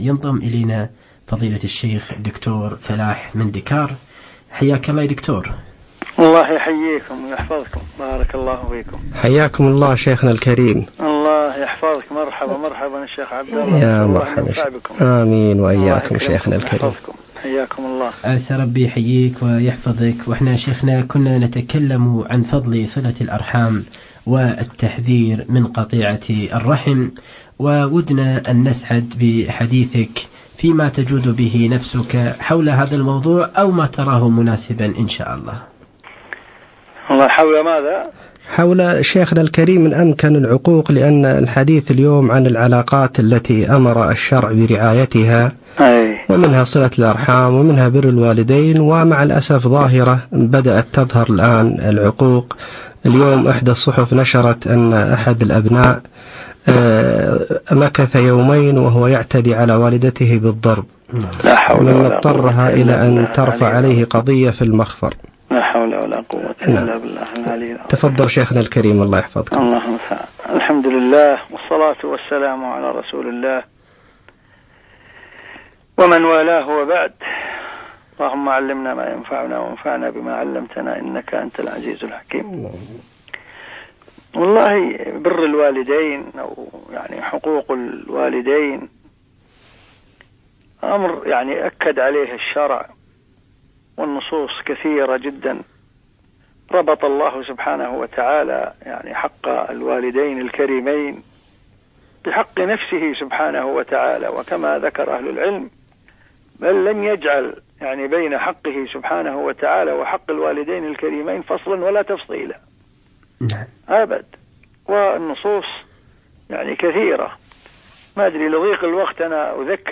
مداخلة هاتفية عن صلة الرحم - إذاعة القرآن الكريم